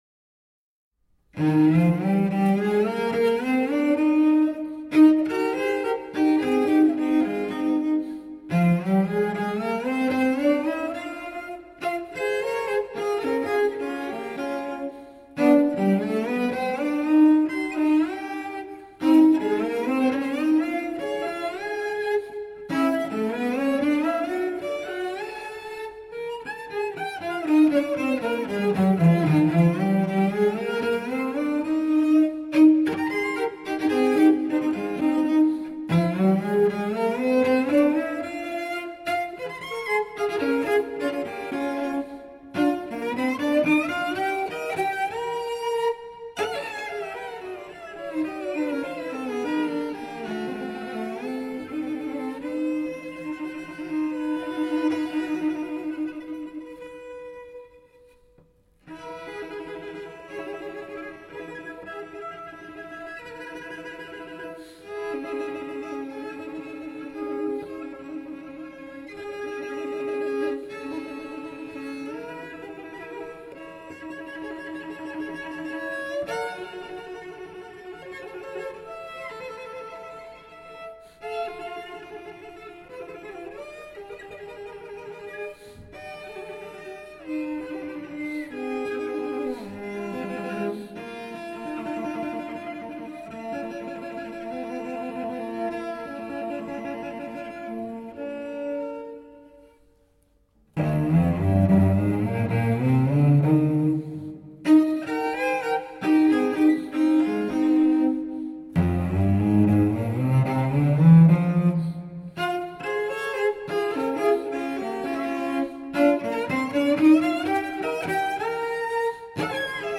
Tagged as: Classical, Romantic Era, Instrumental, Cello